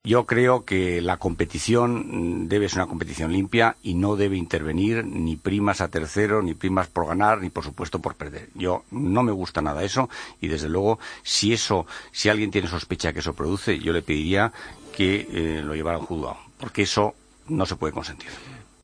El Ministro de Educación, Cultura y Deporte habla sobre la posibilidad de que haya primas a terceros en la última jornada de Liga: "La competición debe ser limpia y no debe haber primas. Si alguien tiene sospechas le pediría que lo llevara a un juzgado"